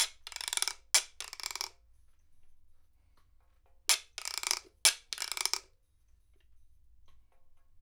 123-PERC1.wav